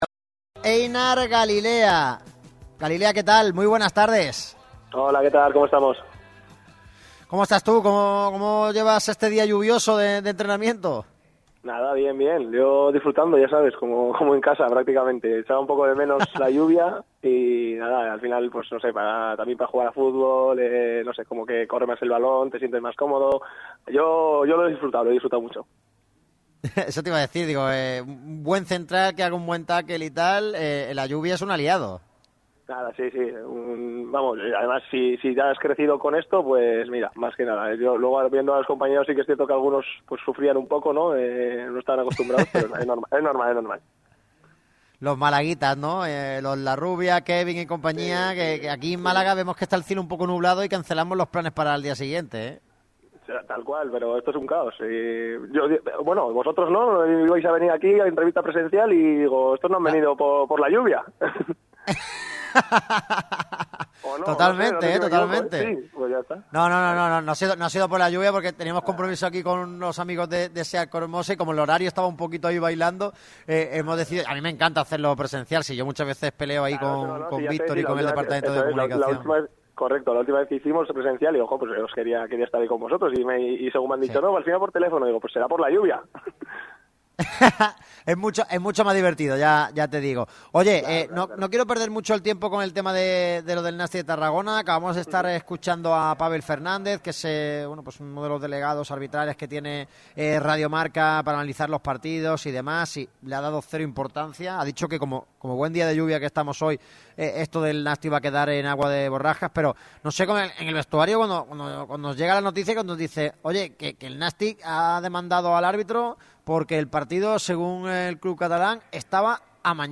El central vasco ha pasado por el micrófono rojo de Radio MARCA Málaga en un momento de la temporada muy importante para él.